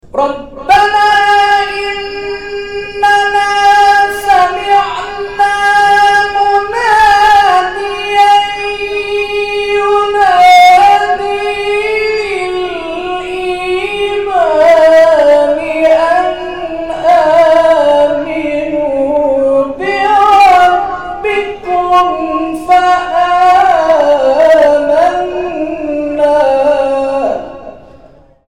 گروه جلسات و محافل: کرسی های تلاوت نفحات القرآن طی هفته گذشته در مساجد احباب الحسین(ع)، عمار یاسر و حضرت ابالفضل(ع) تهران برگزار شد.
در ادامه قطعات تلاوت این کرسی های تلاوت ارائه می شود.